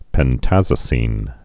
(pĕn-tăzə-sēn)